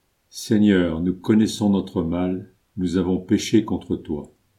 Antienne-JR-14-Seigneur-nous-connaissons-notre-mal.mp3